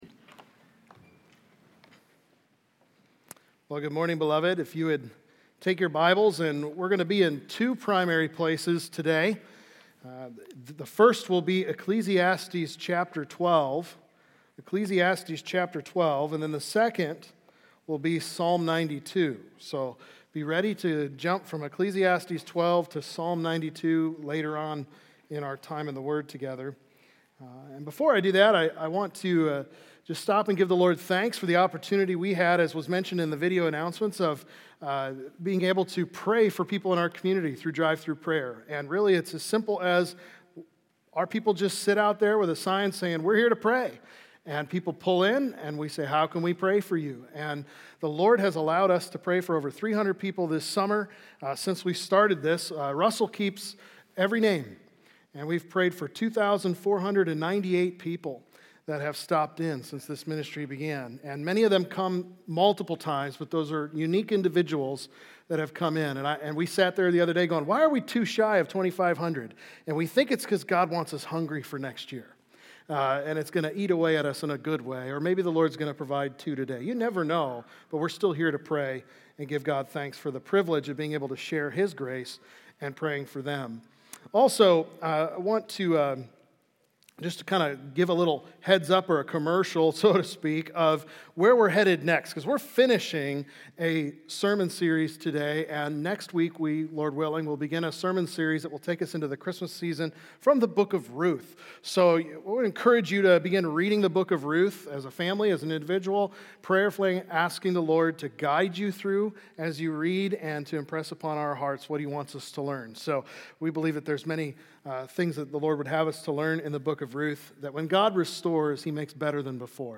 Faithful Through The Ages: Advanced Adulthood | Baptist Church in Jamestown, Ohio, dedicated to a spirit of unity, prayer, and spiritual growth